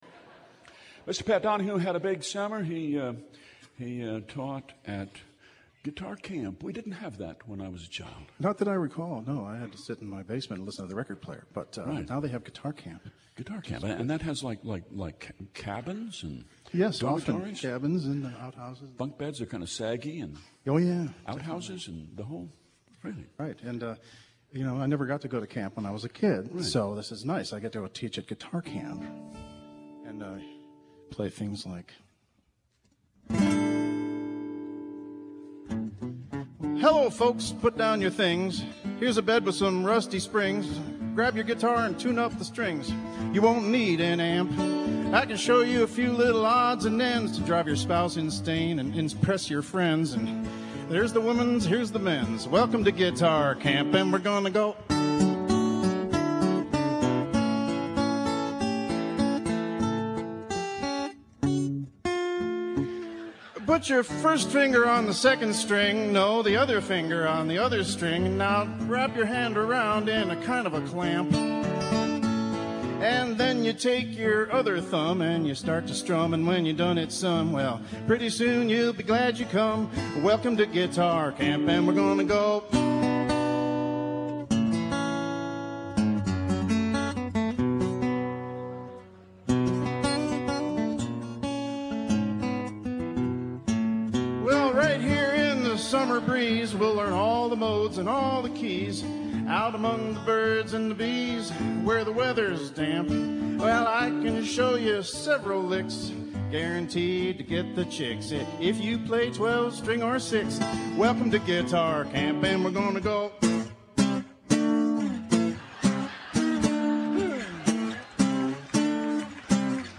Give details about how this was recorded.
Our family enjoys a few NPR radio shows.